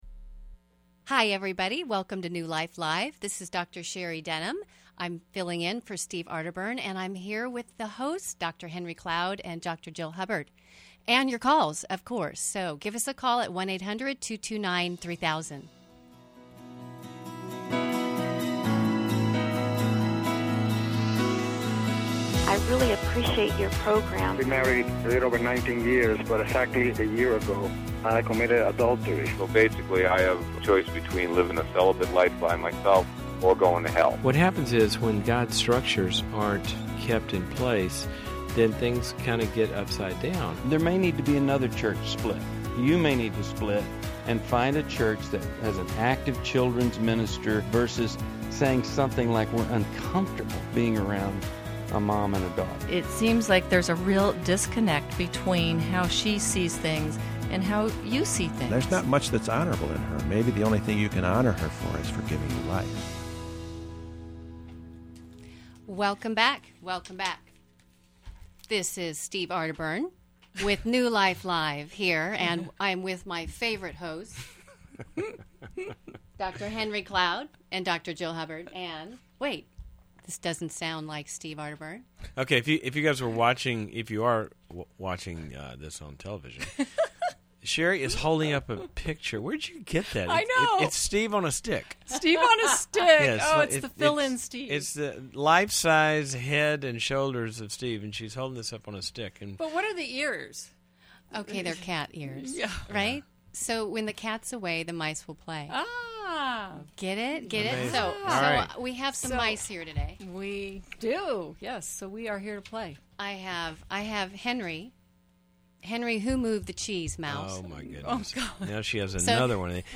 New Life Live: May 31, 2011 - Explore parenting, marriage, and self-worth as experts tackle tough questions on dating boundaries, emotional devaluation, and pornography.
Caller Questions: 1.